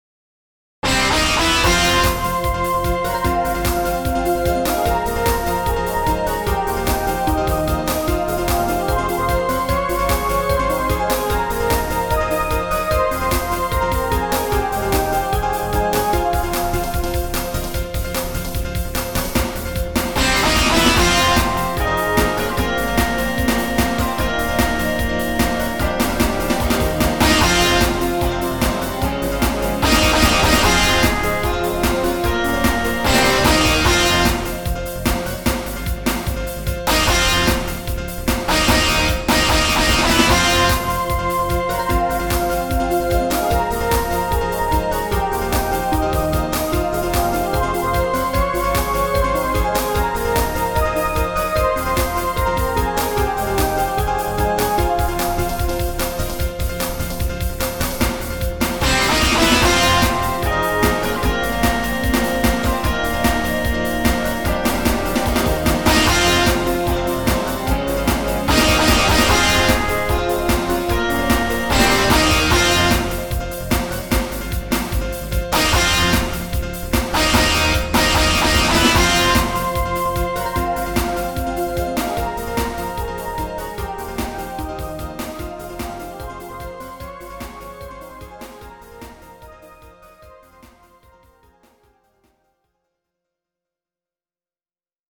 追求するときのテーマ・激しい版。